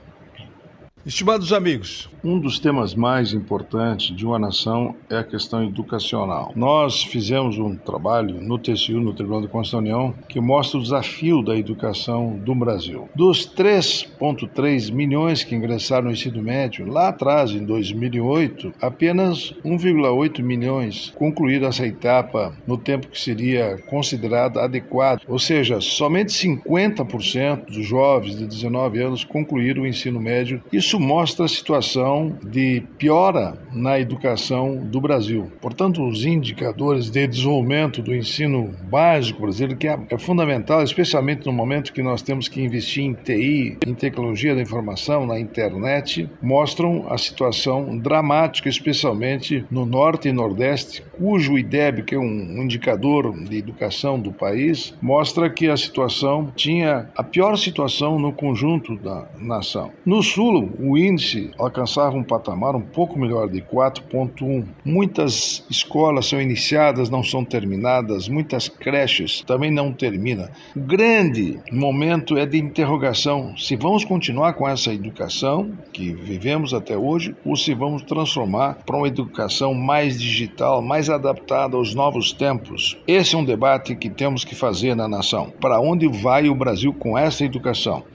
Comentário desta quinta-feira (17/10/24) do ministro do TCU Augusto Nardes.